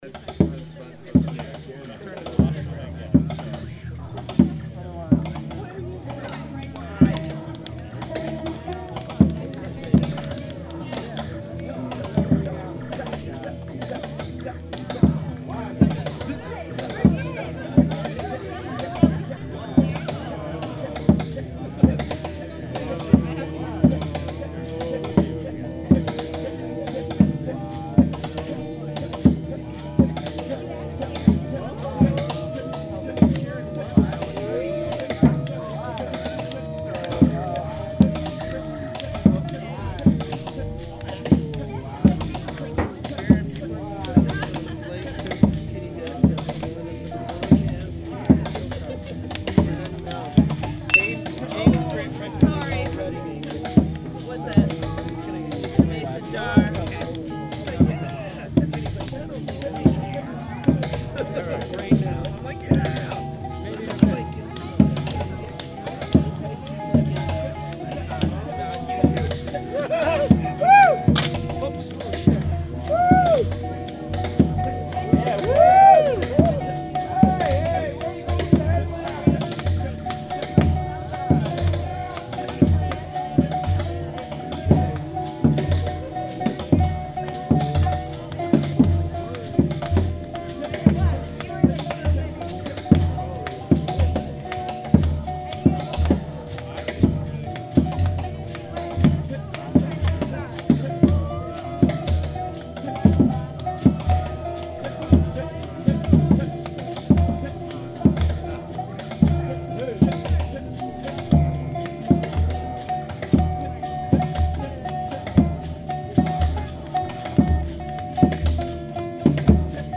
Location: Lake Clara